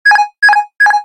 autopilot.ogg